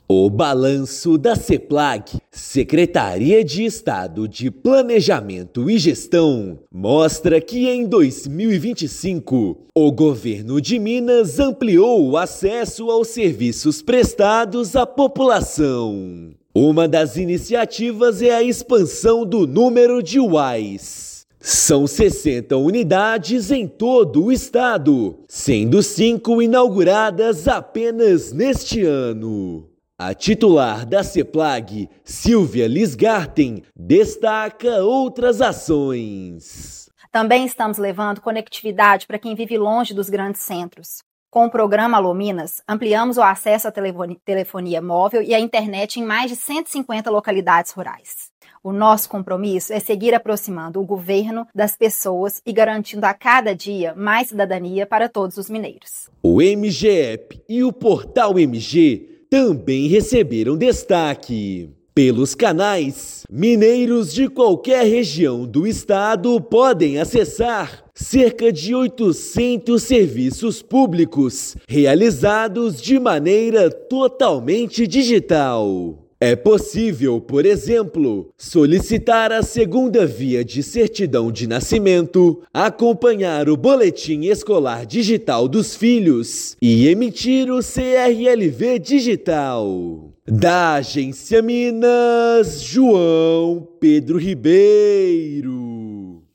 Serviços estaduais estão presentes em todas as regiões mineiras. Seplag-MG inaugurou sete UAIs em 2025 e chegou a 86% no índice de transformação digital. Ouça matéria de rádio.